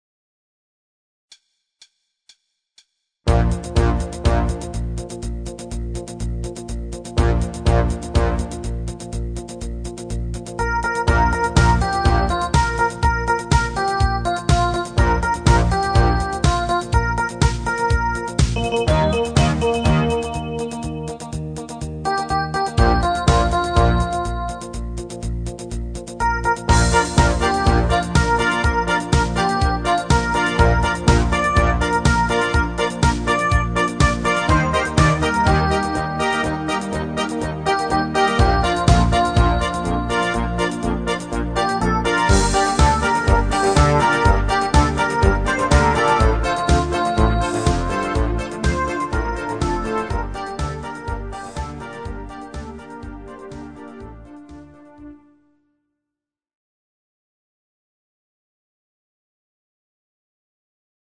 Genre(s): Deutschpop  Partyhits  |  Rhythmus-Style: Discofox